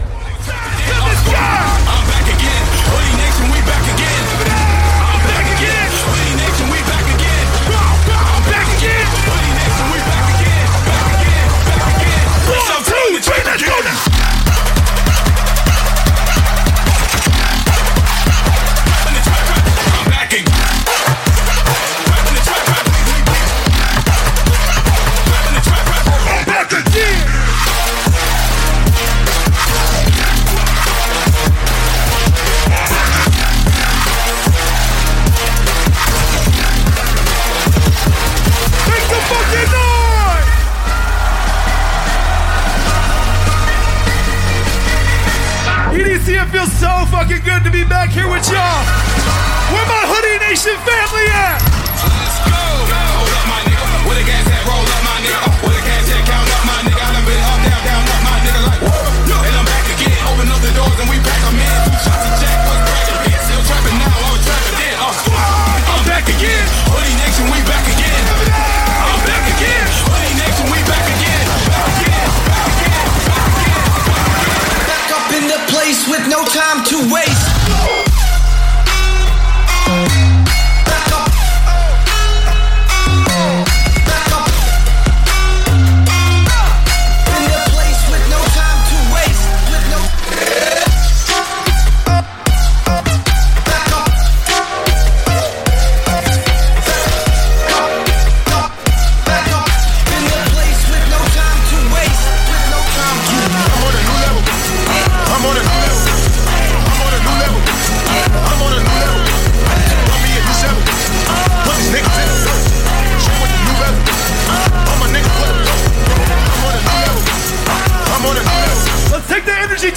Also find other EDM Livesets, DJ Mixes
Liveset/DJ mix